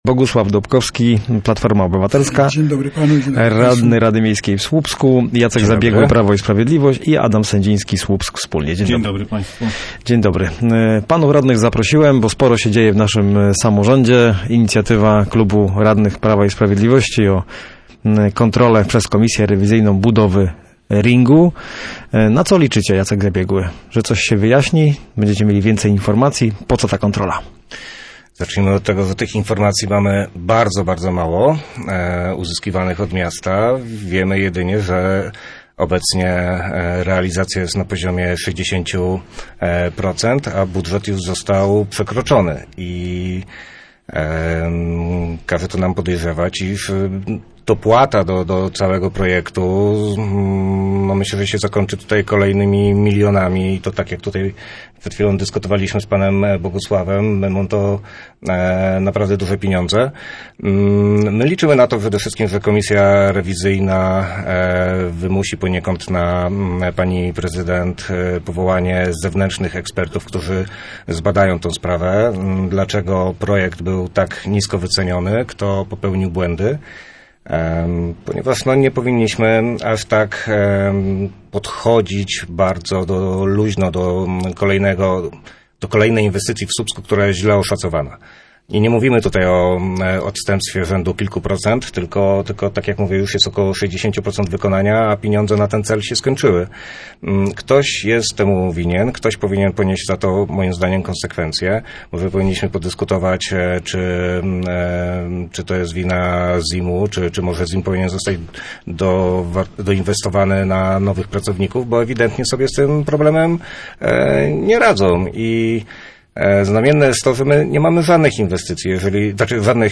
Radni byli gośćmi miejskiego programu Radia Gdańsk Studio Słupsk 102 FM.